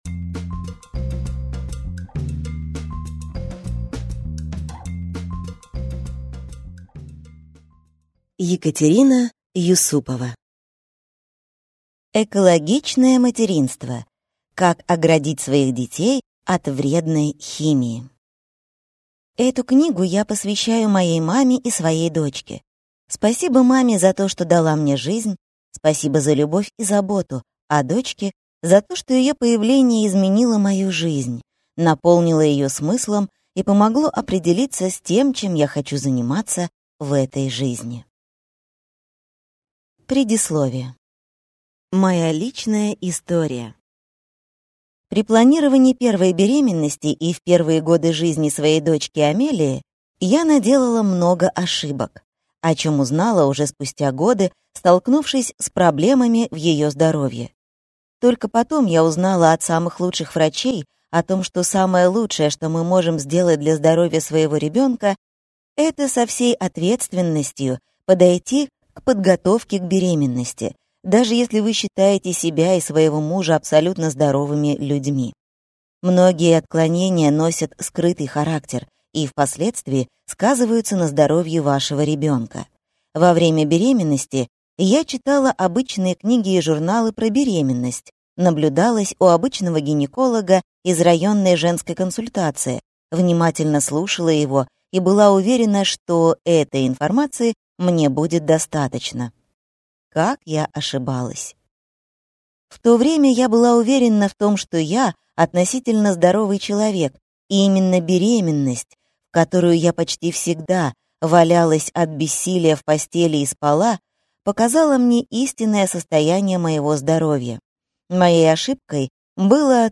Аудиокнига Экологичное материнство. Как оградить своих детей от вредной химии | Библиотека аудиокниг